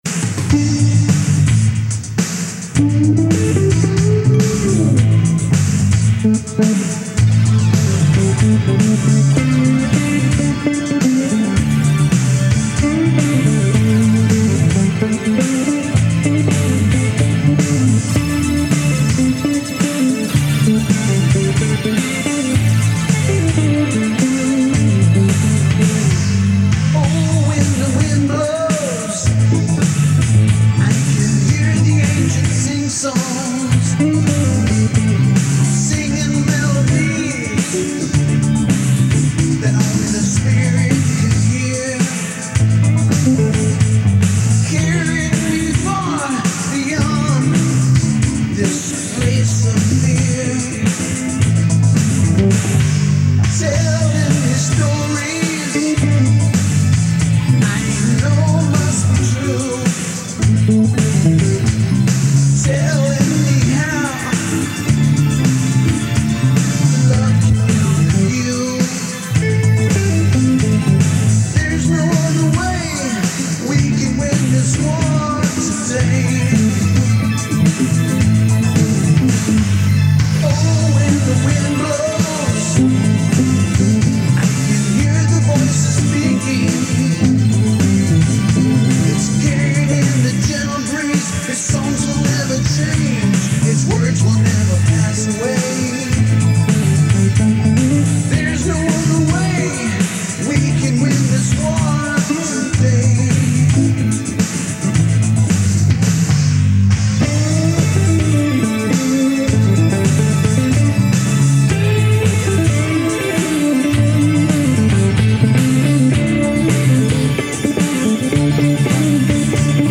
NATIVE AMERICAN CONTEMPORY